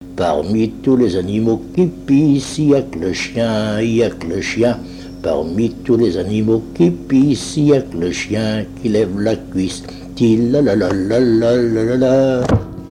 danse : scottich trois pas
Genre brève
Pièce musicale inédite